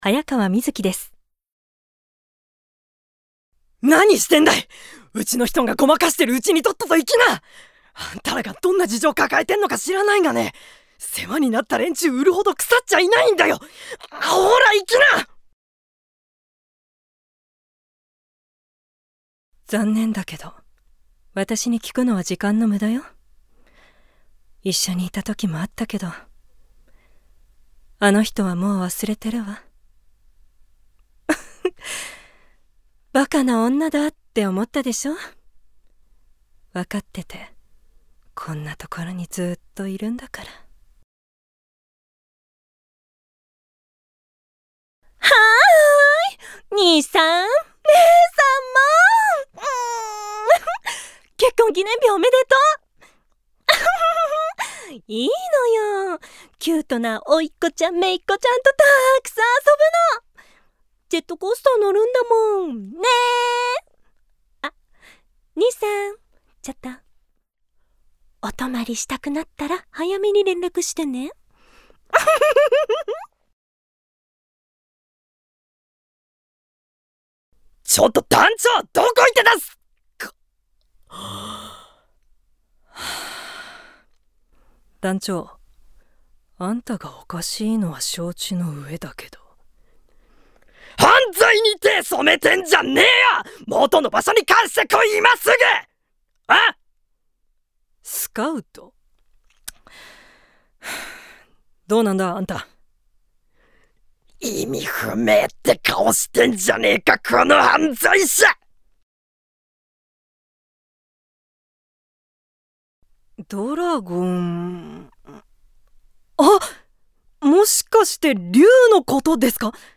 大阪弁 趣味・特技： 空想にふける・カラオケ・散歩・サスペンスを見る・電話対応 資格： 漢字検定準2級・英語技能検定準2級・普通自動車運転免許 音域： Ｆ3～Ｃ＃ VOICE SAMPLE https